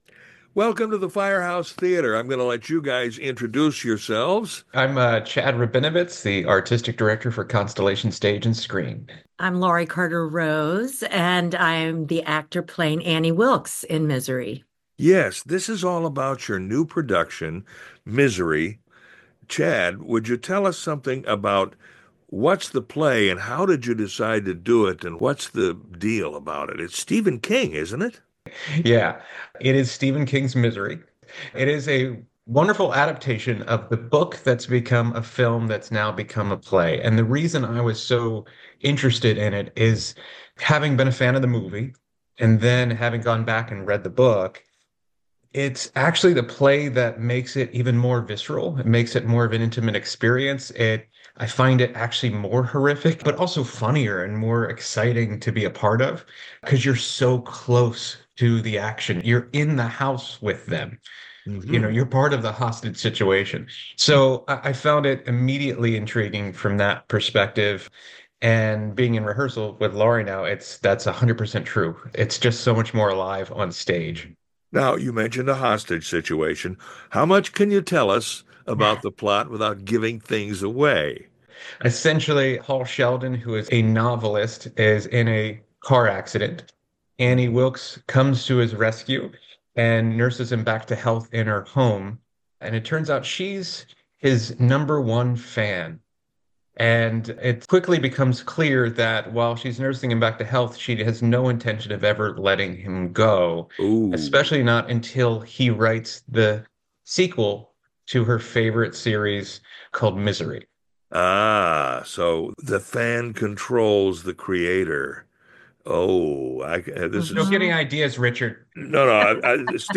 Misery Interview
Misery-WFHB-Radio-Interview.mp3